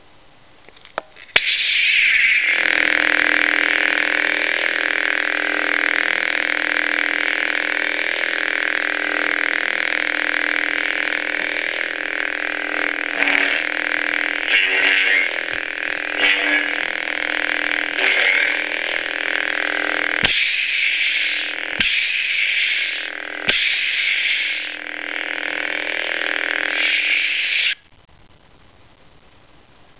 アバウトに音種を解説：起動＝光刃が出る音。駆動＝ブ〜ンというハム音。移動＝振った時に鳴るヴォ〜ンという音。衝撃＝刃をぶつけた時に鳴るバシ〜ンという音。収縮＝光刃が消える音。